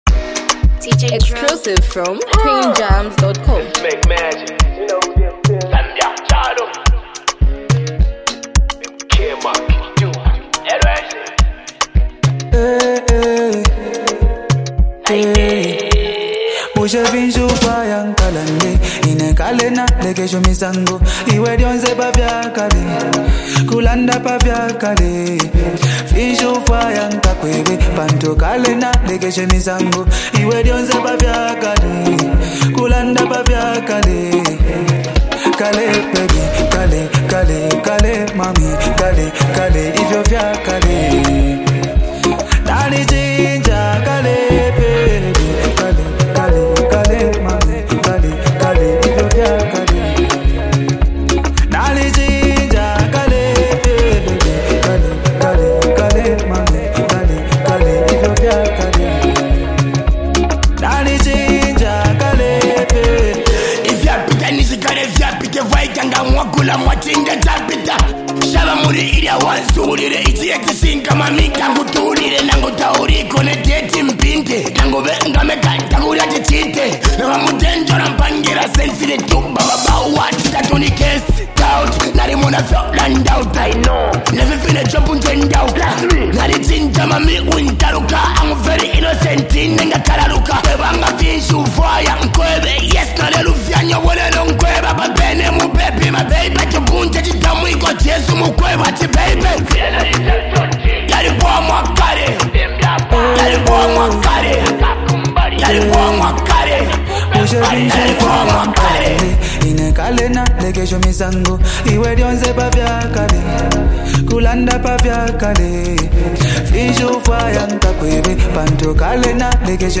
carries a reflective tone
adding warmth and relatability through his smooth vocals.